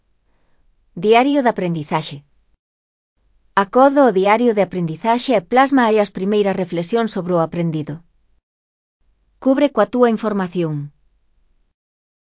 Elaboración propia (proxecto cREAgal) con apoio de IA voz sintética xerada co modelo Celtia. DA fase 1 (CC BY-NC-SA)